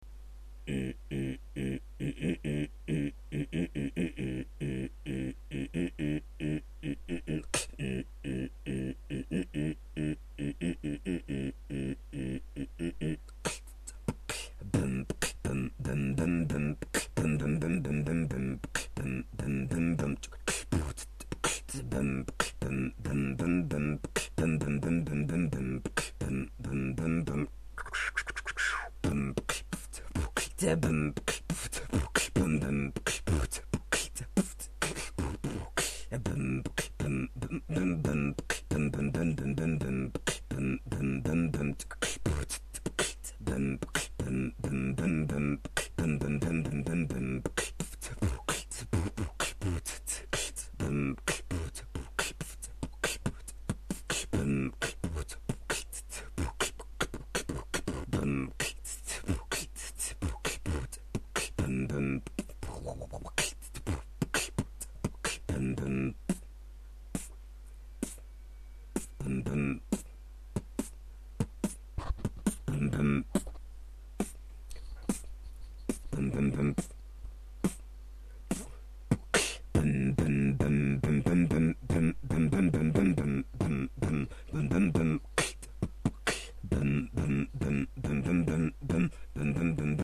В конце обрывается)) чот камп у меня затупил)))
Такта нет.. с метрономом надо практиковаться.. включил метроном и все.. бит задуман неплохо.. но вот такт вообще никакой.. практикуйся.. такт - это одно из главных то нужно битбоксеру.. happy